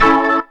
B3 AMAJ 1.wav